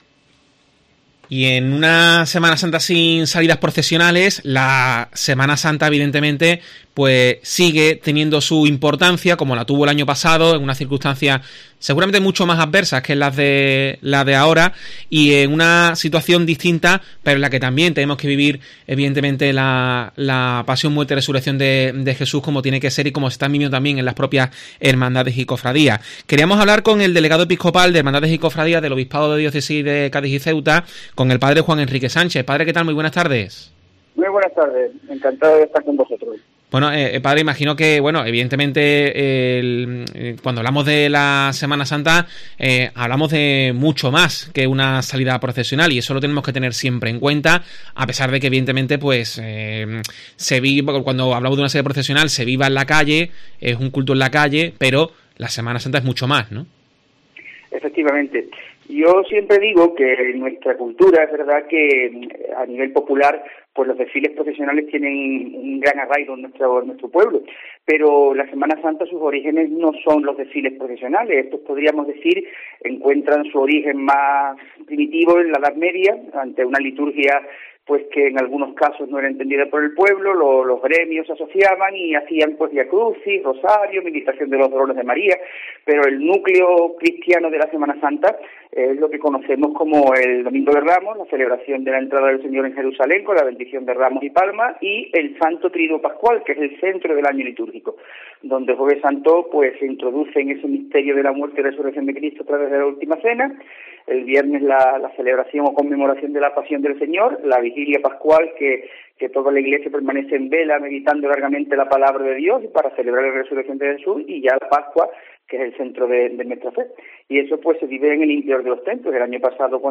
Sin procesiones tampoco en este 2022, analiza en los micrófonos de la Cadena COPE como ha sido esta Cuaresma y la Semana Santa que viene.